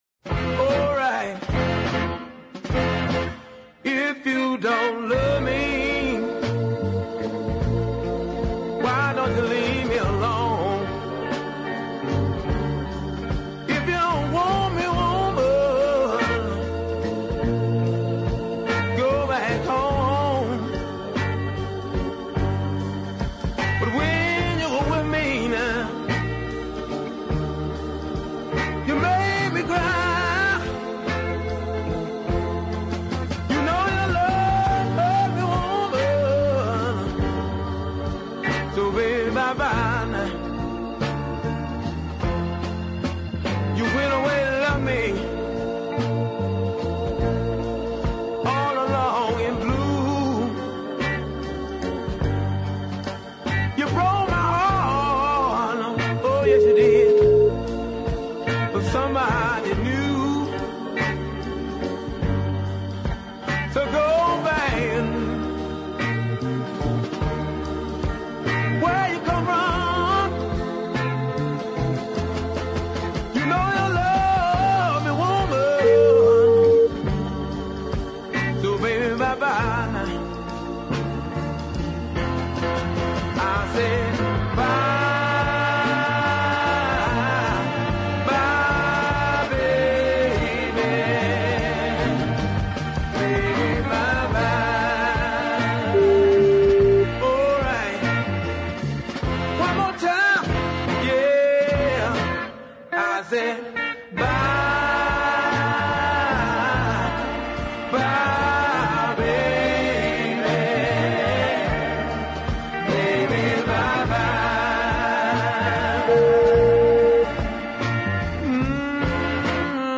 is a wonderful deep soul ballad